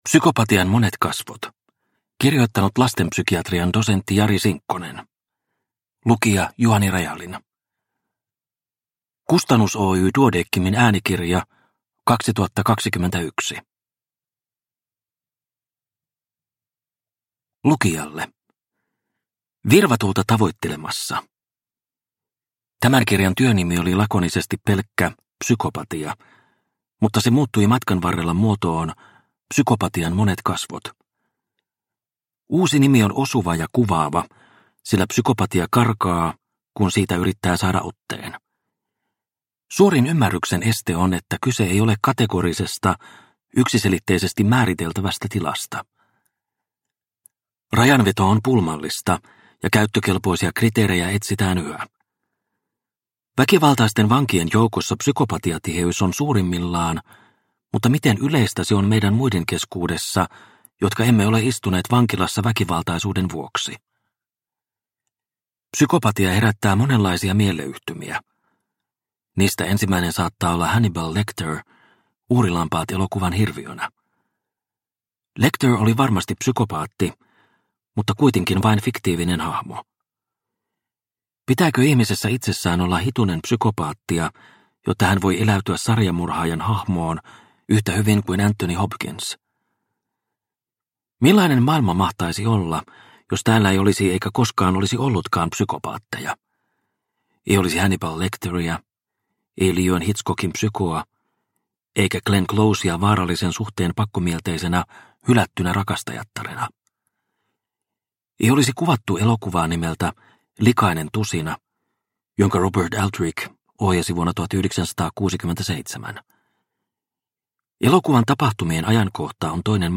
Psykopatian monet kasvot – Ljudbok – Laddas ner
Uppläsare